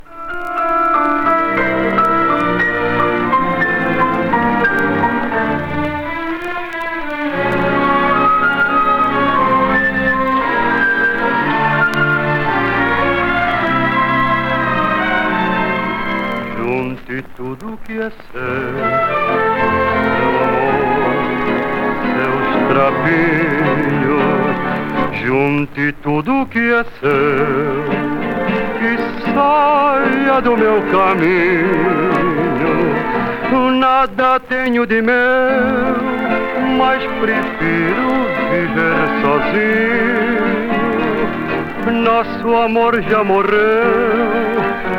Pop, Vocal, World, Samba　Brazil　12inchレコード　33rpm　Mono